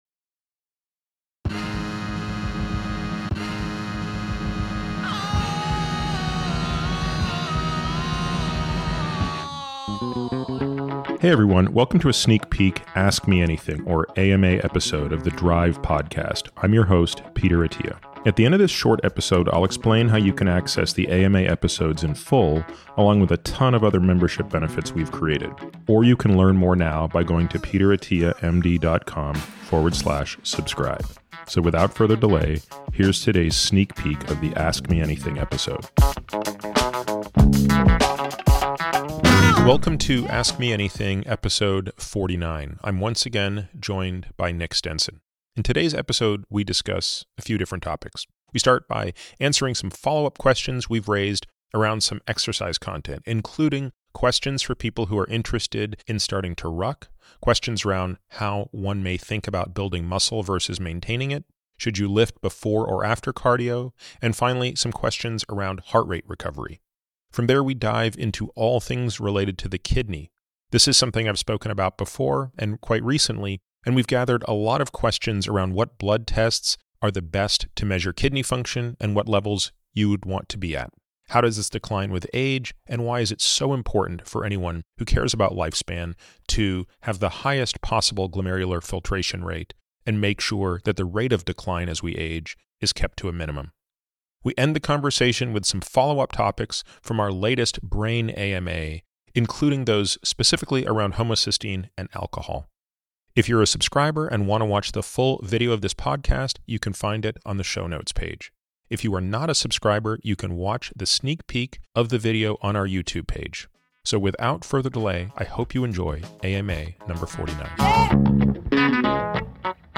In this “Ask Me Anything” (AMA) episode, Peter addresses follow-up questions related to recent conversations around exercise, kidney function, and brain health. He begins with the topic of exercise, covering aspects such as starting rucking, transitioning from muscle building to maintenance, the optimal order of lifting weights and cardio, and exploring heart rate recovery.